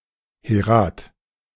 pronunciation recording
heˈʁaːt
Male voice, recorded by native German speaker from Berlin, Germany
Røde NT-USB